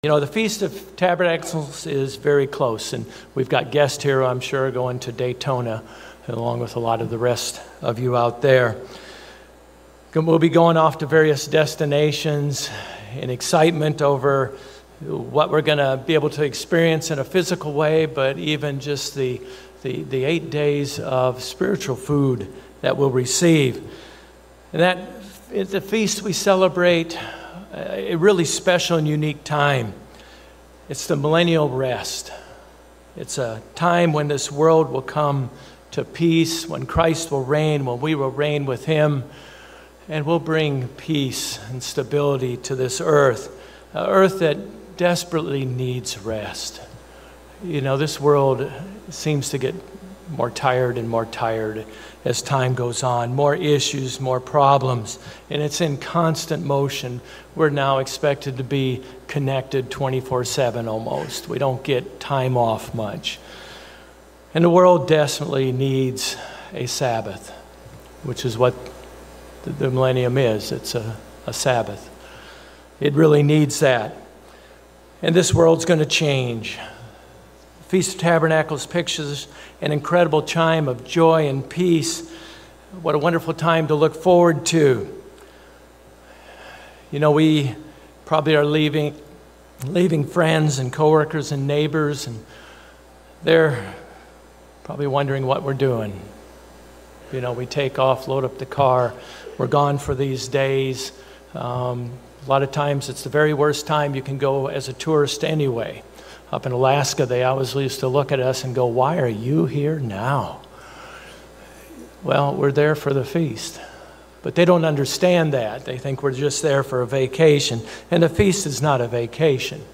What is the place of the First Fruits, the Feast of Tabernacles, the 8th Day Holy Day, the Millennium, and the 2nd Resurrection in God’s plan of Salvation? These and other topics related to salvation are discussed in this message.